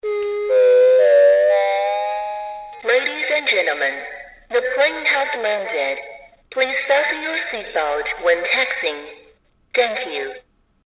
• voice over PA - toy airplane.mp3
Recorded with a Steinberg Sterling Audio ST66 Tube, in a small apartment studio.
voice_over_pa_-_toy_airplane_jh5.wav